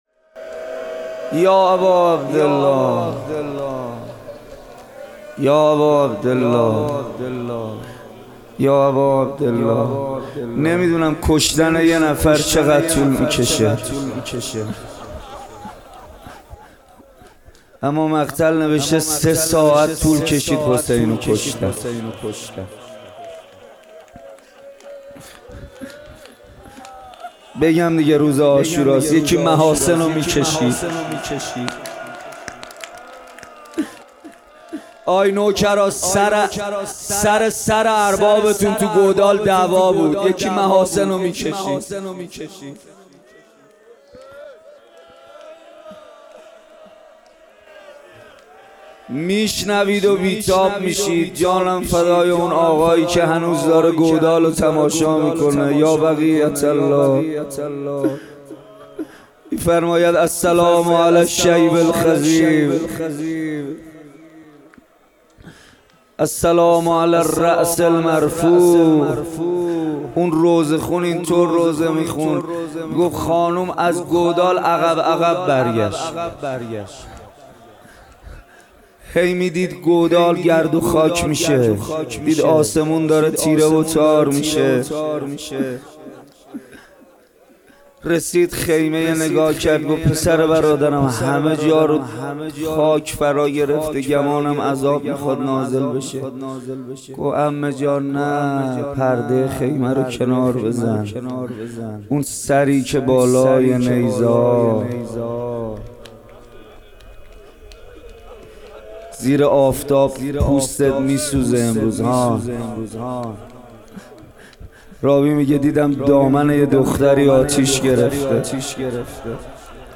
مداحی کربلایی محمدحسین پویانفر | محرم 1399 هیئت ریحانه النبی تهران